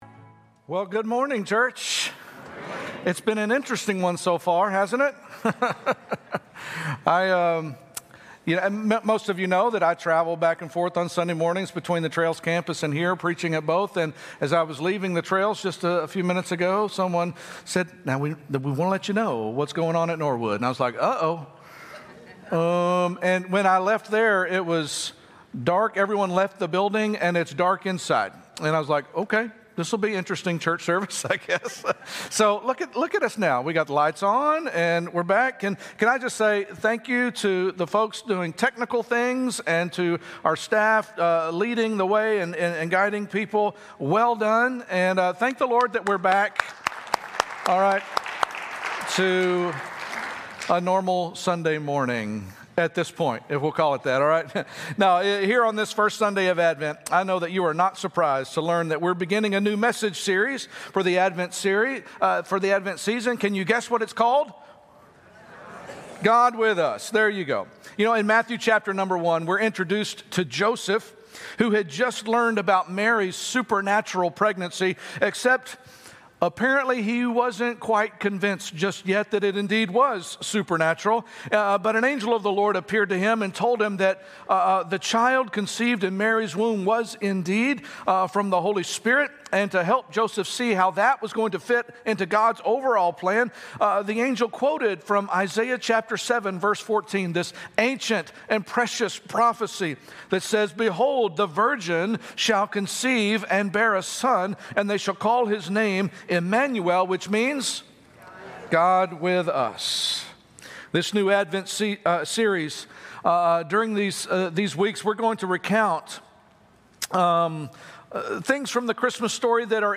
Sunday Message - God With Us In the Garden